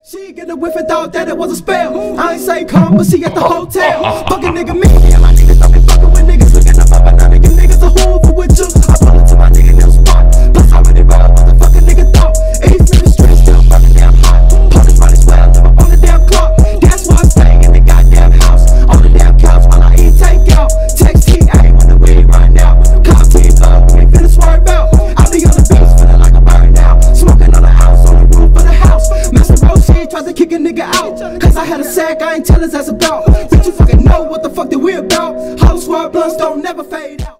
Ремикс # Рэп и Хип Хоп
громкие